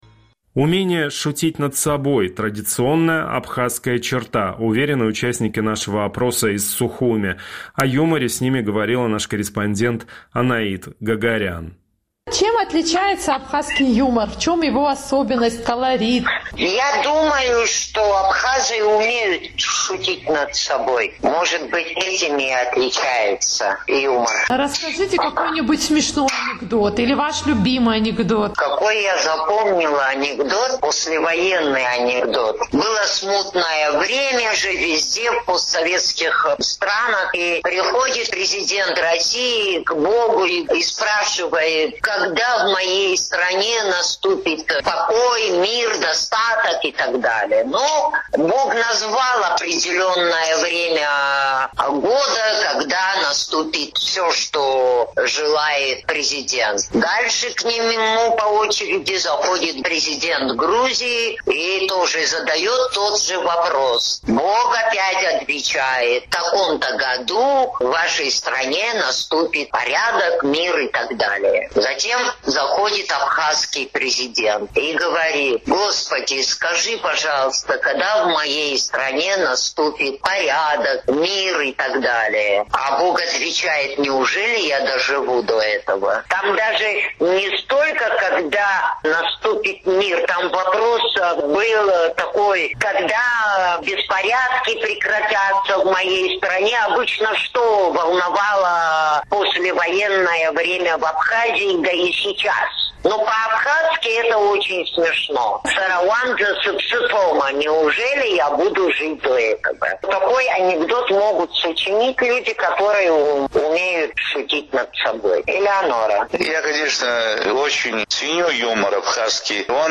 Сухумский опрос – к 1 апреля